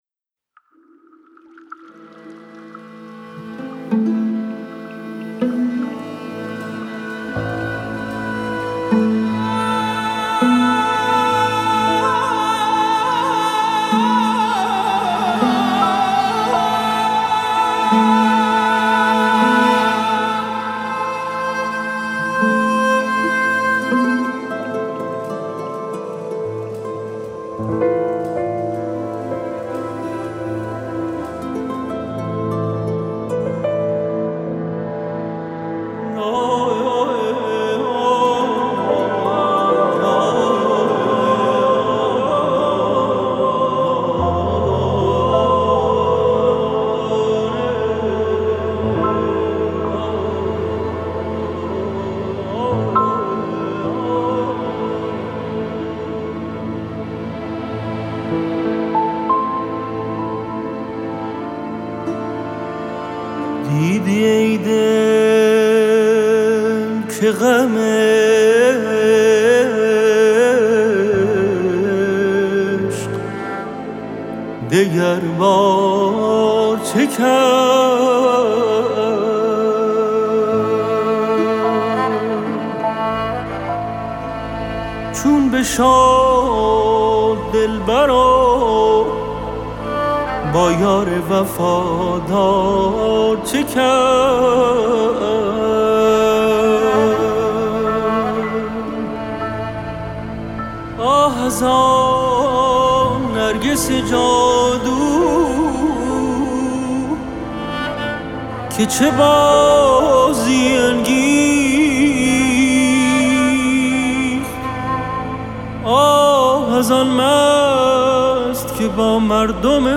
ویولن و آلتو
کمانچه
ویولنسل